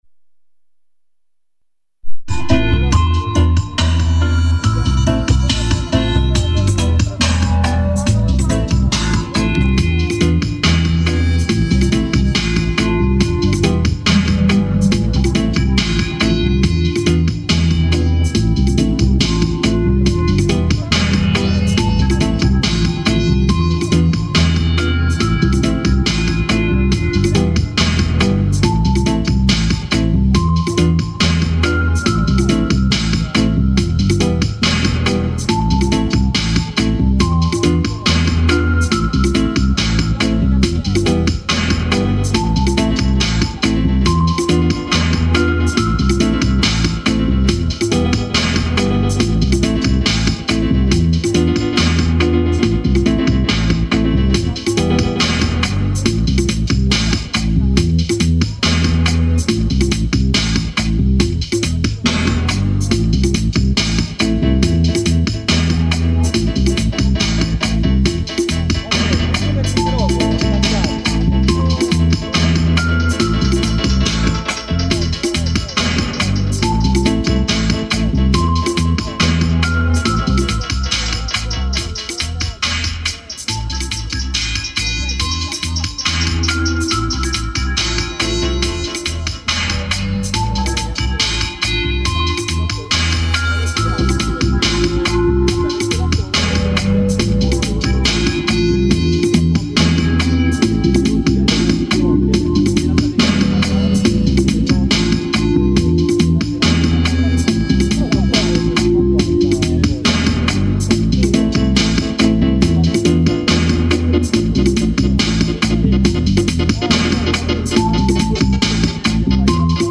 Dub live set
recorded live with microphone, rough with quality.